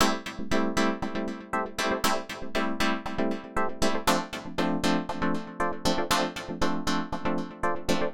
28 Chords PT1.wav